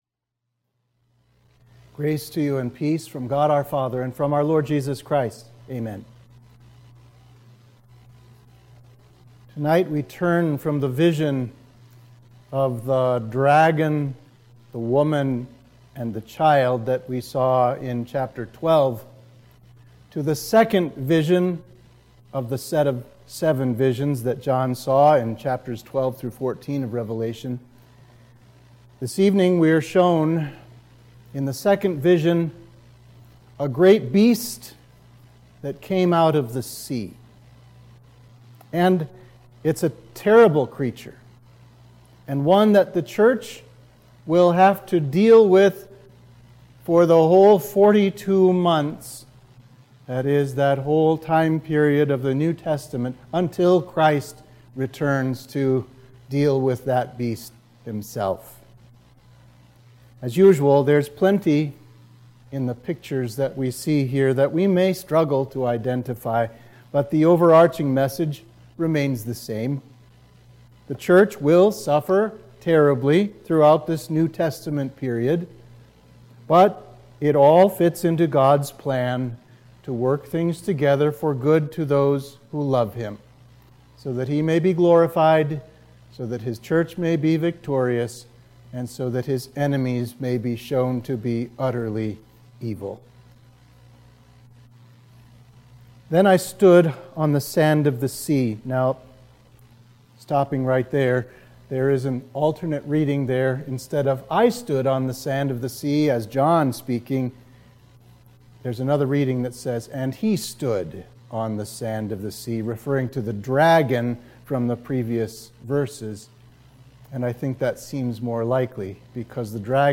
Sermon for Midweek of Trinity 5